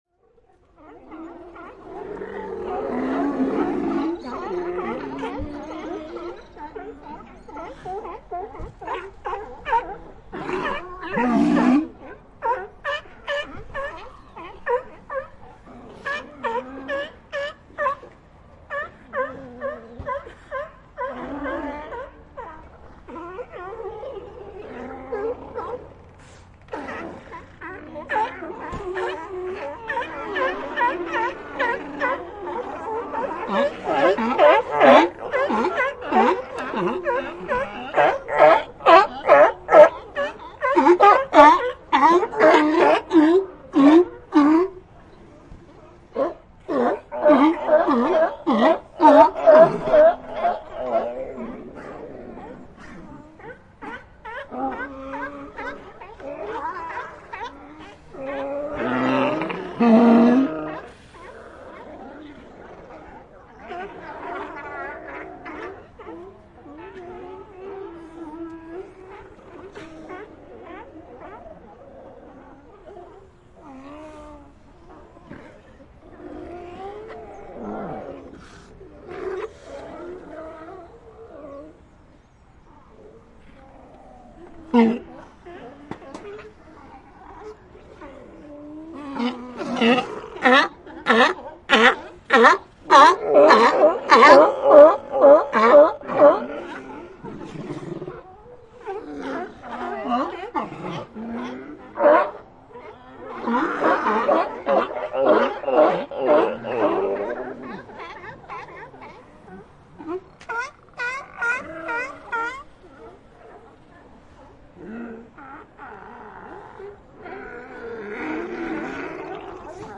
Colony Of Sea Lions On Land Barking And Vocalizing Téléchargement d'Effet Sonore